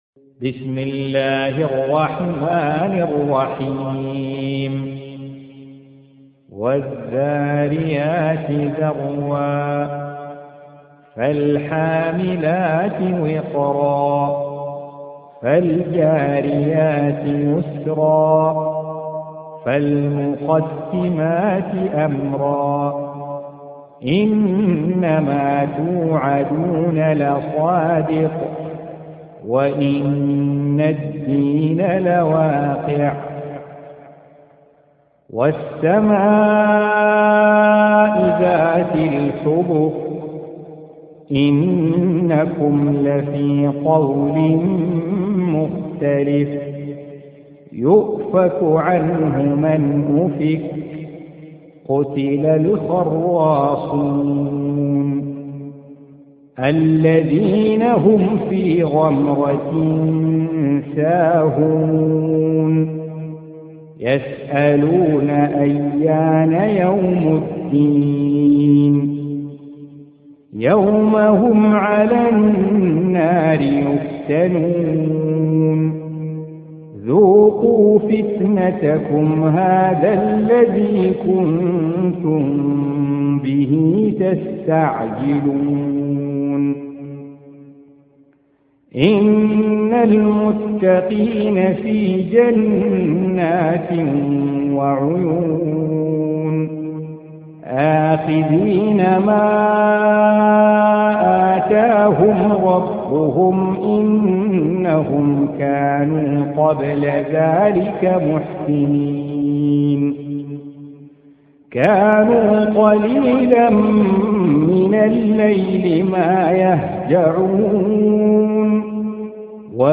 51. Surah Az-Z�riy�t سورة الذاريات Audio Quran Tarteel Recitation
Surah Sequence تتابع السورة Download Surah حمّل السورة Reciting Murattalah Audio for 51. Surah Az-Z�riy�t سورة الذاريات N.B *Surah Includes Al-Basmalah Reciters Sequents تتابع التلاوات Reciters Repeats تكرار التلاوات